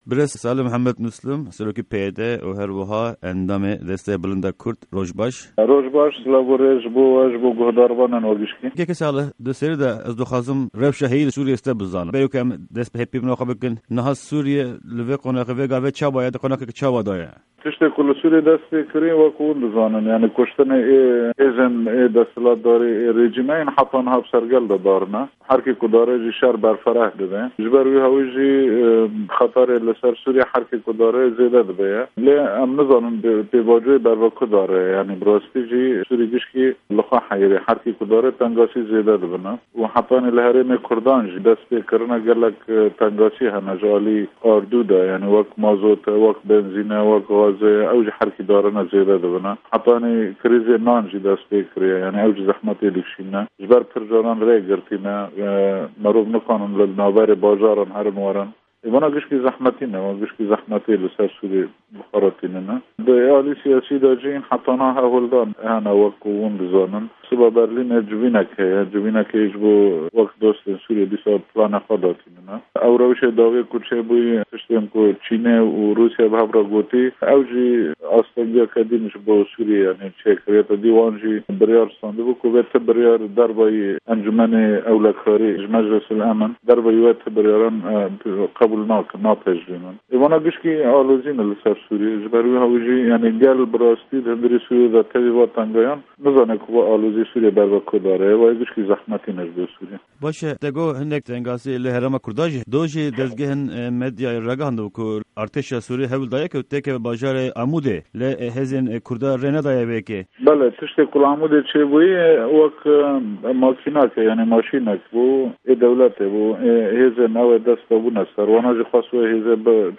Interview_Salih_Mislim_08_28_RR